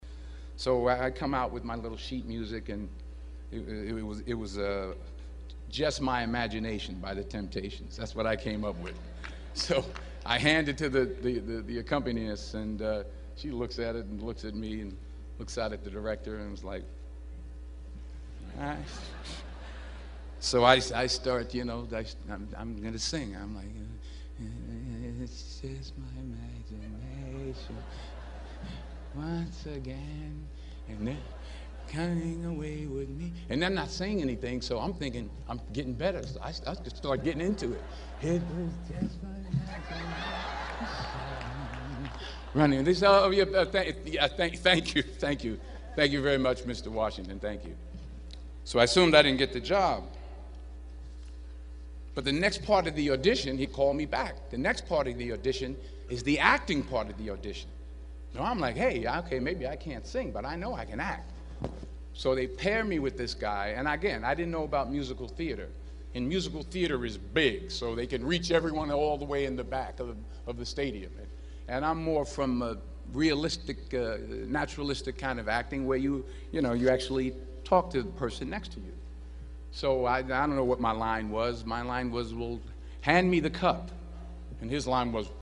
公众人物毕业演讲第424期:丹泽尔2011宾夕法尼亚大学(8) 听力文件下载—在线英语听力室